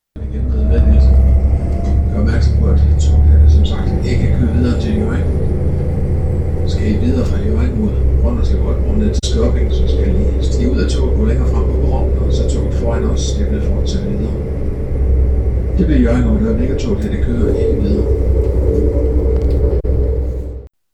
Højttalerudkald i tog